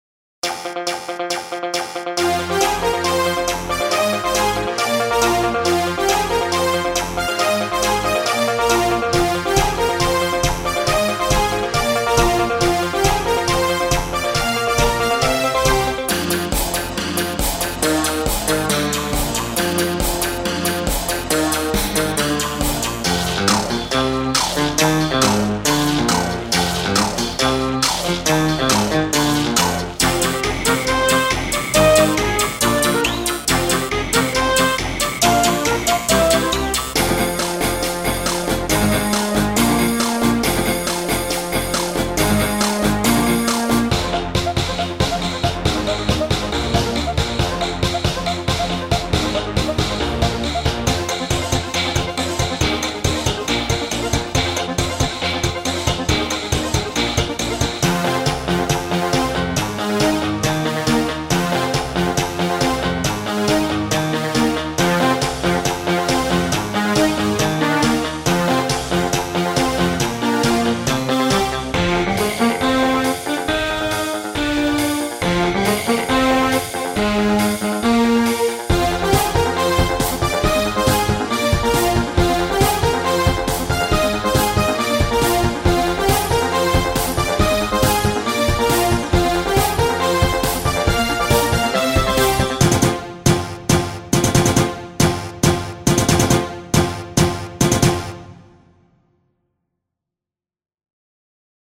Western track for retro and casual.